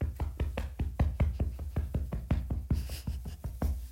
Goose Running At Me.aiff
Category 😂 Memes